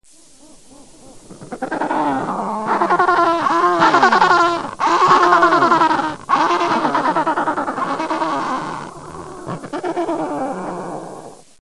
Pinguino - Suoni 39748
• Categoria: Pinguino